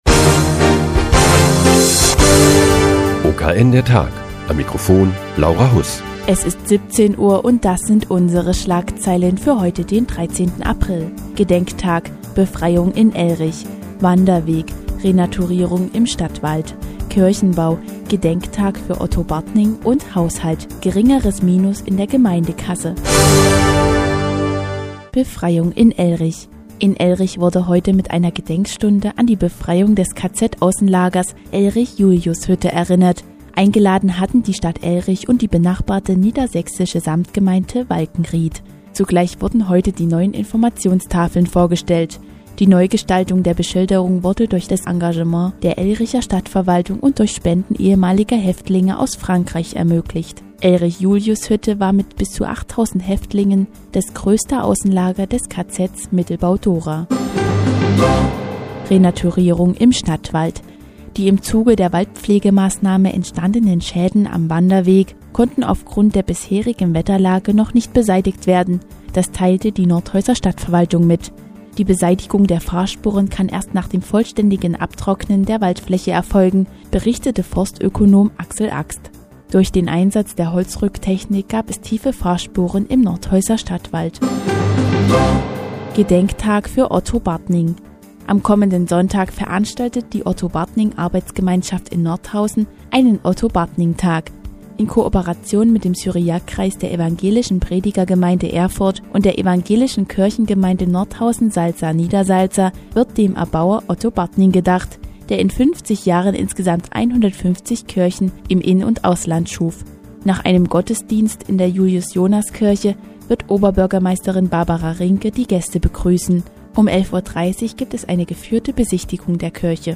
Die tägliche Nachrichtensendung des OKN ist nun auch in der nnz zu hören. Heute geht es um die Renaturierung des Nordhäuser Stadtwaldes und einen Gedenktag für Otto Bartning, der 150 Kirchen im In-und Ausland schuf.